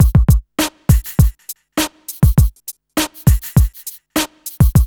HF101BEAT3-R.wav